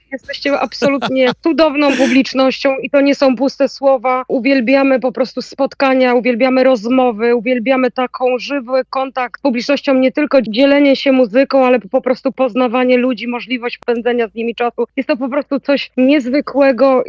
Na antenie Radia Deon Chicago lider grupy, kompozytor, instrumentalista i wokalista – Grzegorz Stróżniak, oraz Marta Cugier – wokalistka pisząca teksty oraz menager, zapowiadają swoje przybycie do Wietrznego Miasta.